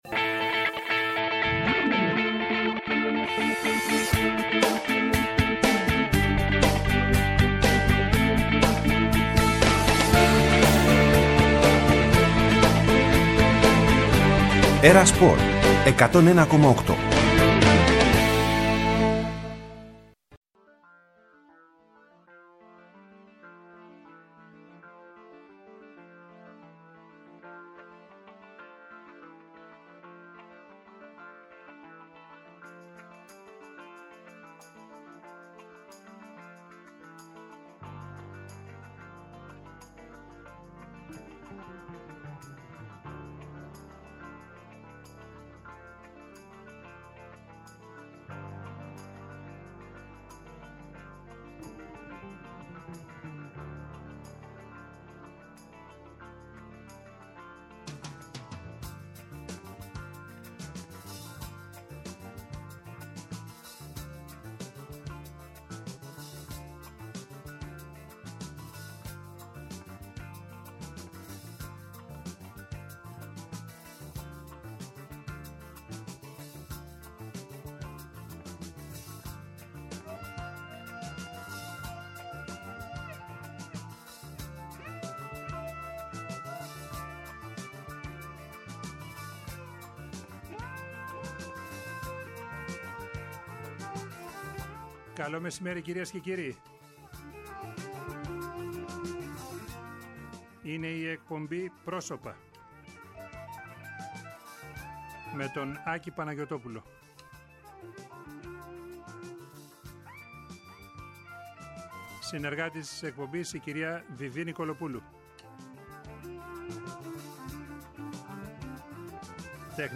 Κάθε συνέντευξη διαρκεί μία ώρα, χρόνος αρκετός για εκείνους που έχουν κάτι καινούργιο να σας πουν